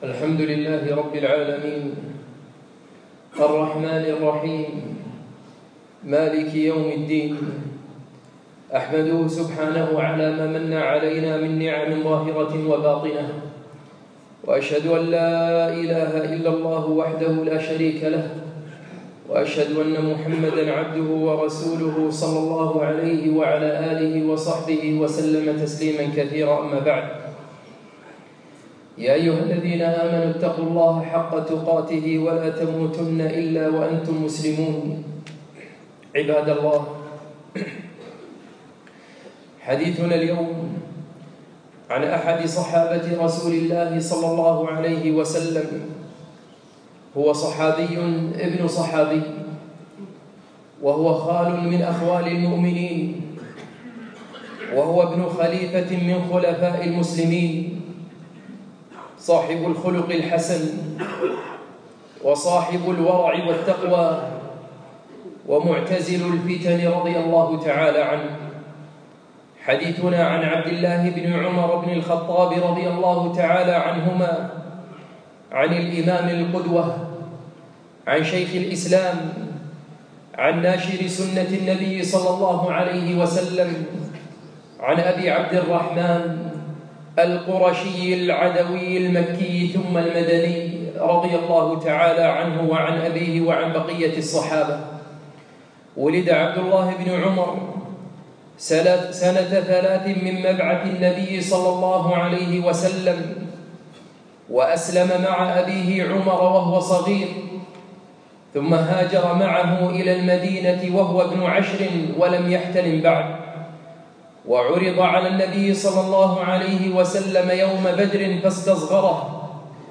يوم الأثنين 9 شعبان 1438 الموافق 5 5 2017 في مسجد العلاء بن عقبة الفردوس